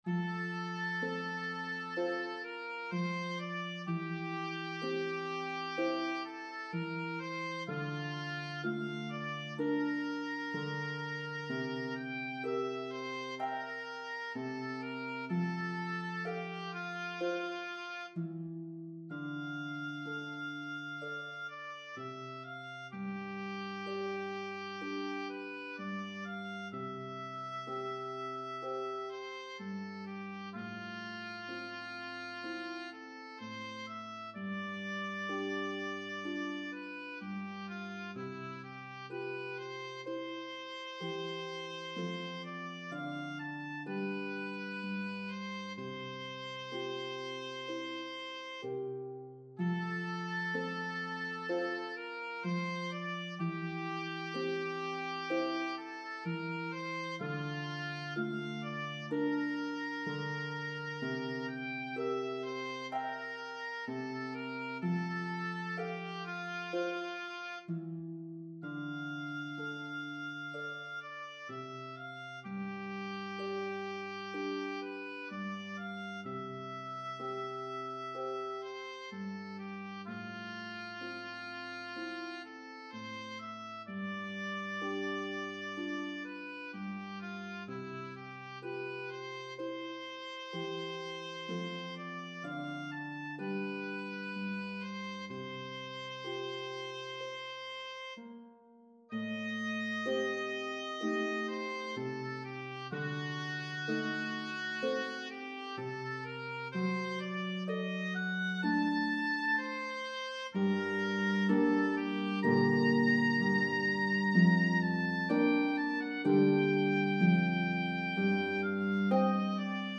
Harp and Oboe version